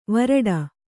♪ varaḍa